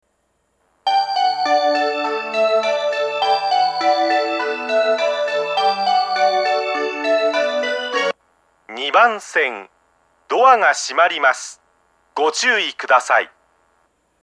また、２０１０年３月以前に放送装置を更新し、メロディーに低音ノイズが被るようになりました。
熱海・東京方面   ２番線接近放送
２番線発車メロディー